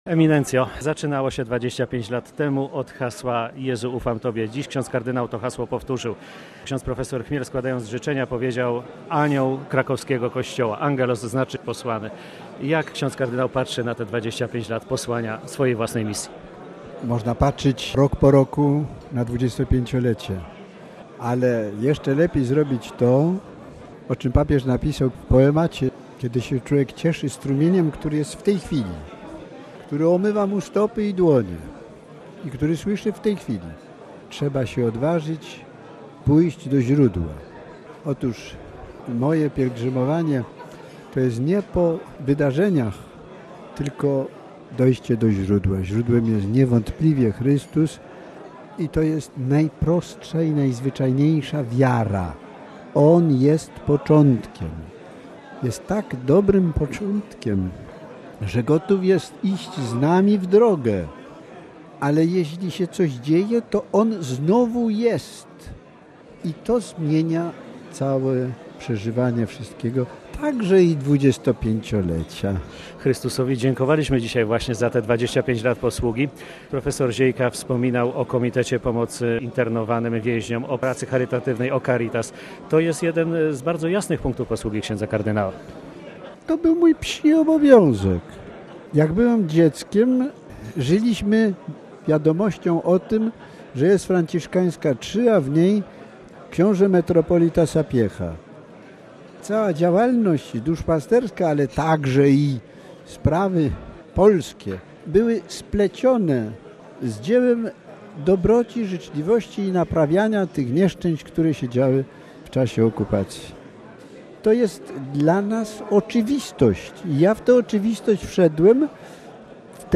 Przy okazji obchodów srebrnego jubileuszu podkreślił w Krakowie, że na swoją posługę patrzy głównie w perspektywie wiary. Z kard. Macharskim rozmawiał